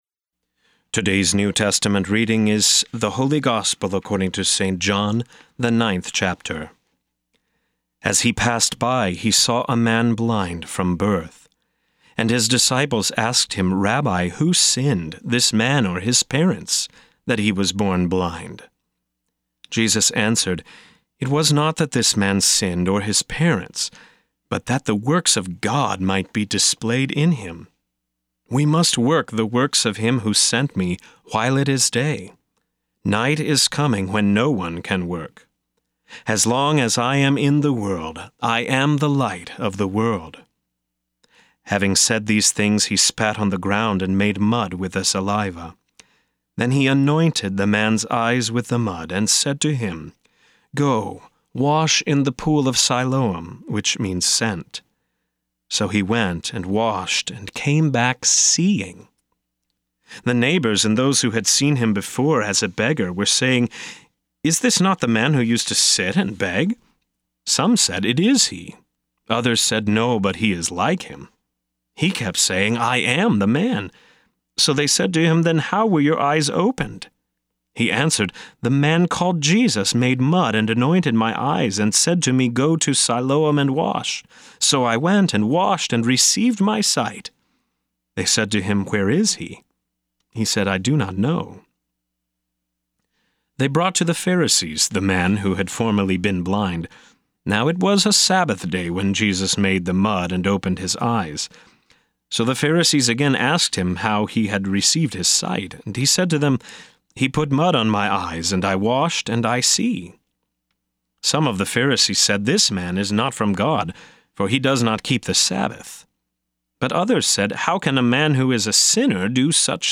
This is a rebroadcast from February 27, 2017. Hear a guest pastor give a short sermonette based on the day’s Daily Lectionary New Testament text during Morning and Evening Prayer.